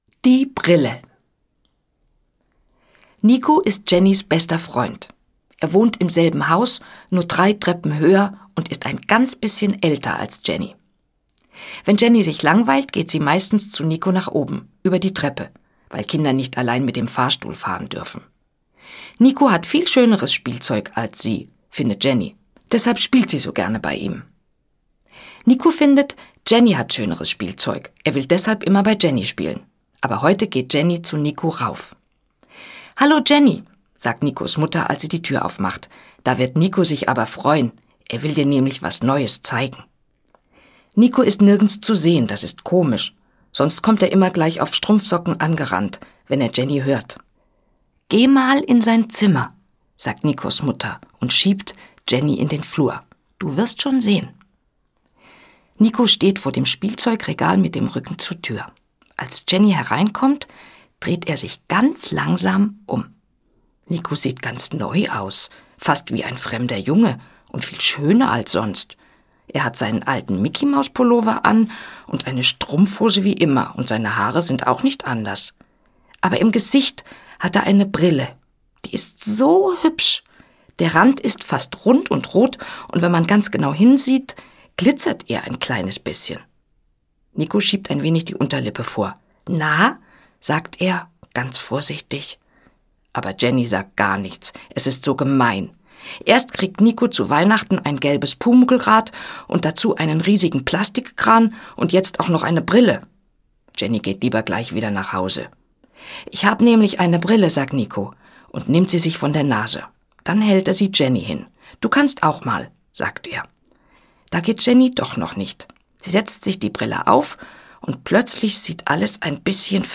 Hörbuch Jenny ist meistens schön friedlich, Kirsten Boie.